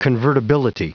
Prononciation du mot convertibility en anglais (fichier audio)
convertibility.wav